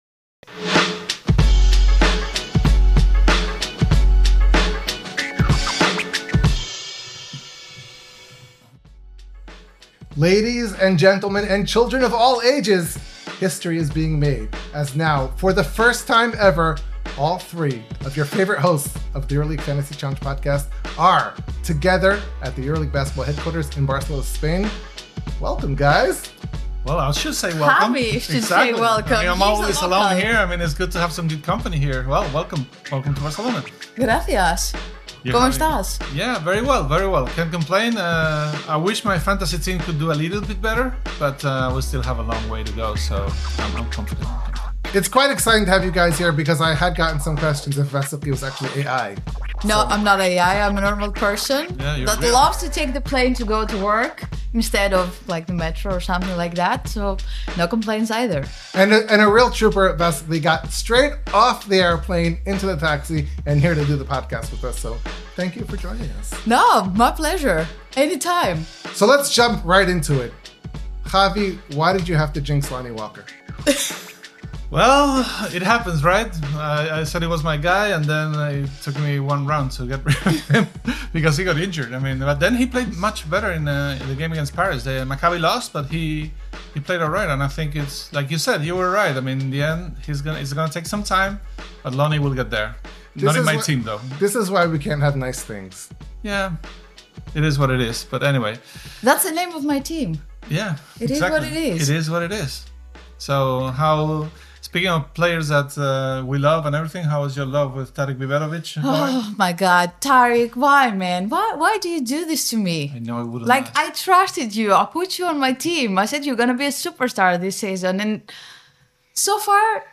Welcome to a special episode recorded live from EuroLeague Basketball Headquarters in Barcelona! All three hosts unite to break down the critical fantasy basketball decisions for Round 3 and analyze the biggest EuroLeague storylines.